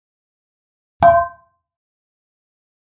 Frying Pan Hit Version 1 - Hard